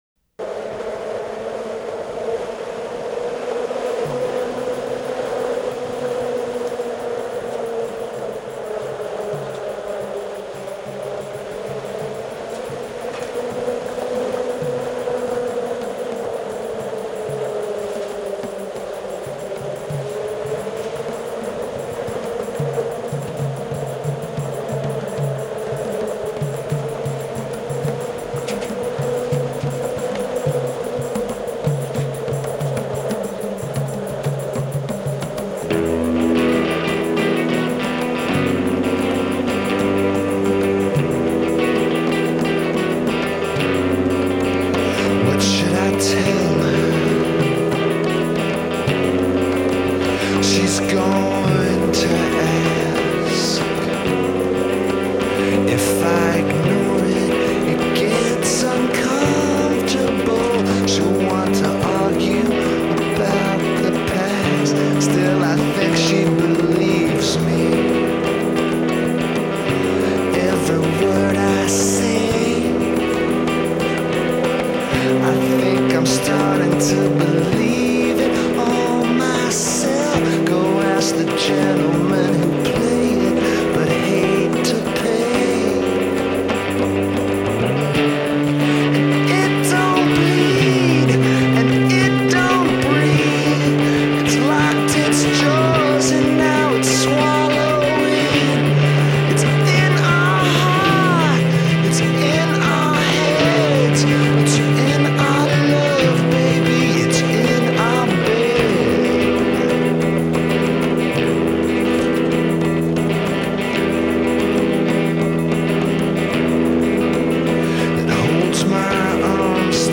Жанр: alternative rock, post punk, grunge